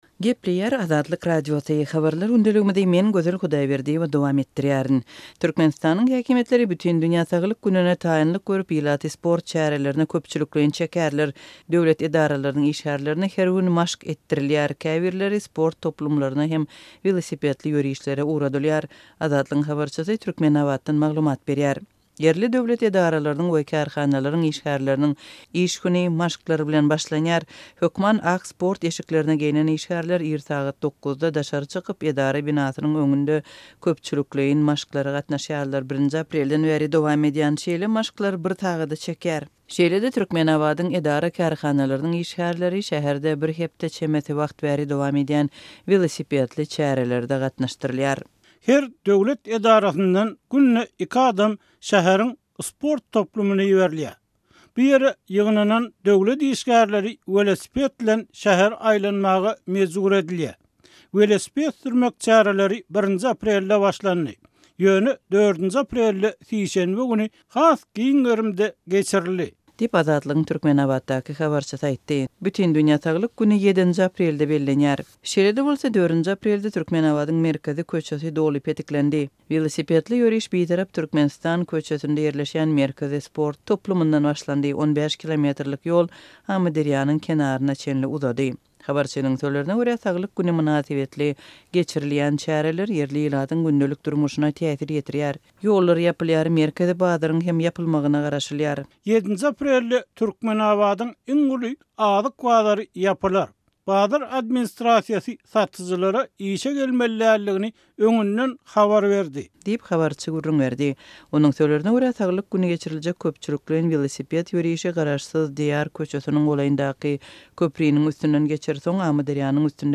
Türkmenistanyň häkimiýetleri Bütindünýä saglyk gününe taýynlyk görüp, ilaty sport çärelerine köpçülikleýin çekýärler. Döwlet edaralarynyň işgärlerine her gün maşk etdirilýär, käbirleri sport toplumlaryna we welosipedli ýörişlere iberilýär. Azatlygyň habarçysy Türkmenabatdan maglumat berýär.